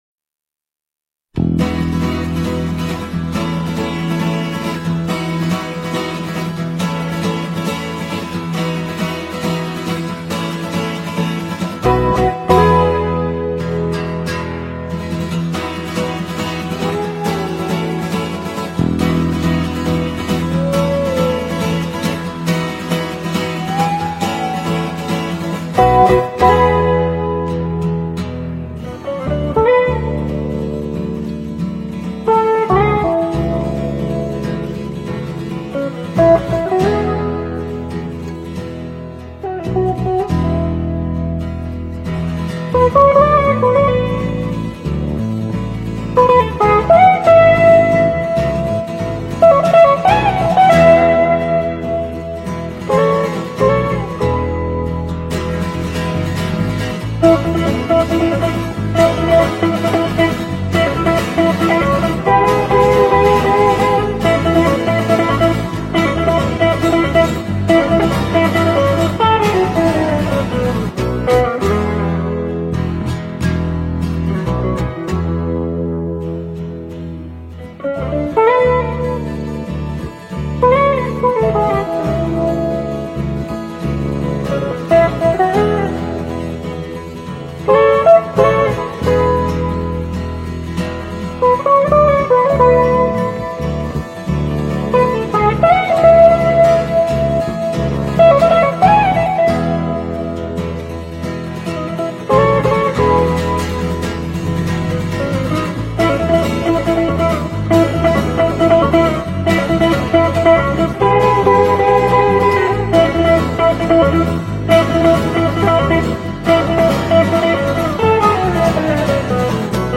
Recorded in Oslo Norway.